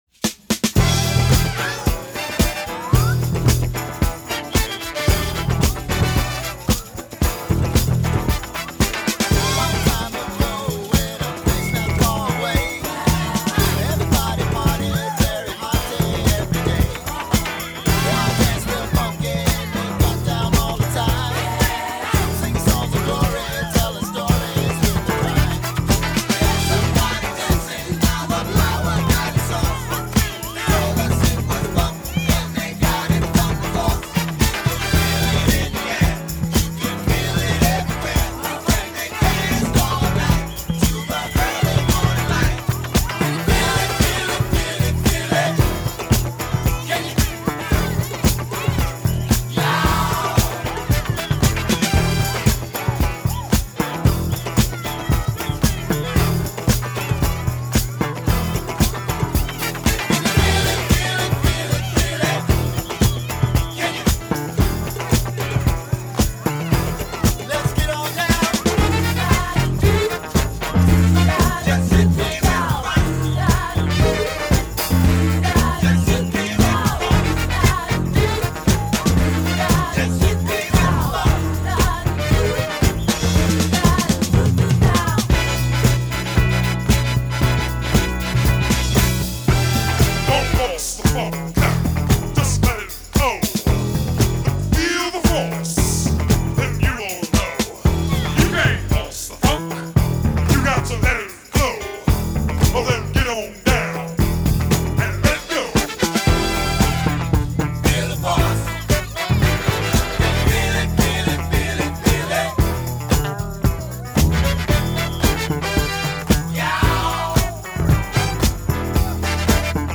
A Mix of Classic and Nu Funk
A Rare Groove